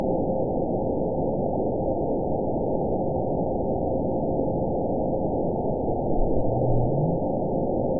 event 911772 date 03/08/22 time 07:33:45 GMT (3 years, 2 months ago) score 9.54 location TSS-AB01 detected by nrw target species NRW annotations +NRW Spectrogram: Frequency (kHz) vs. Time (s) audio not available .wav